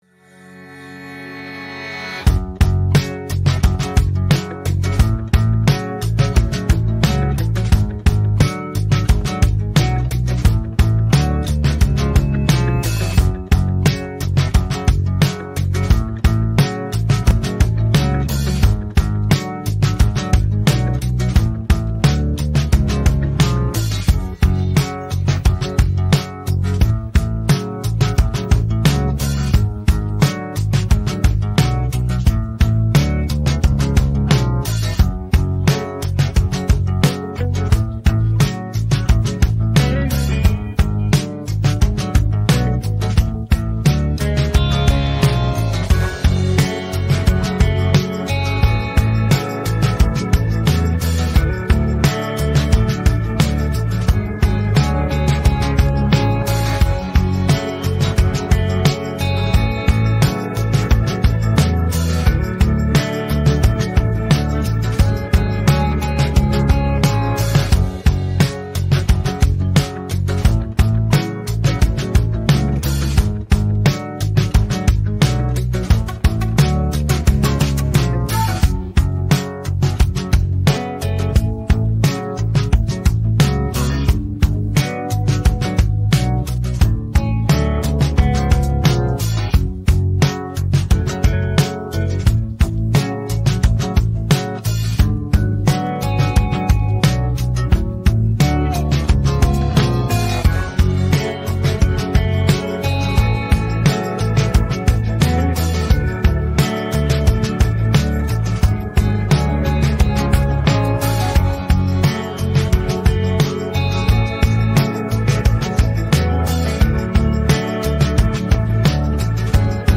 rock караоке 39
Українські хіти караоке